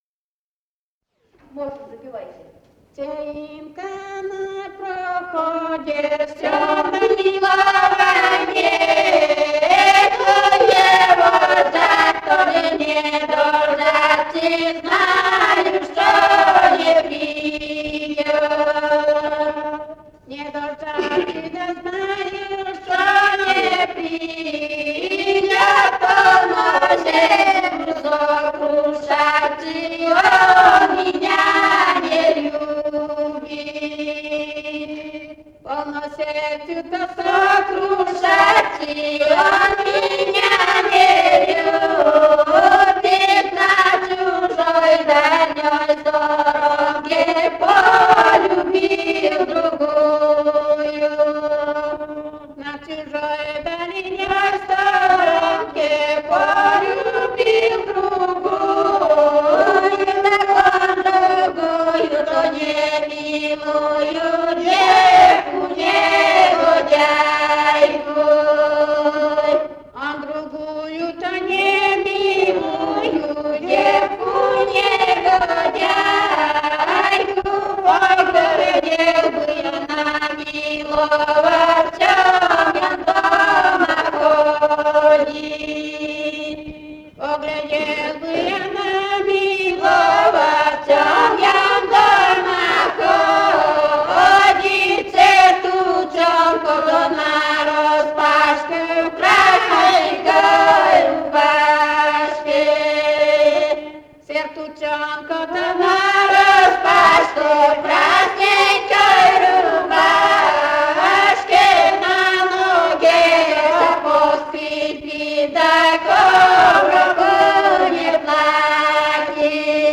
«[Вечер] на проходе» (лирическая).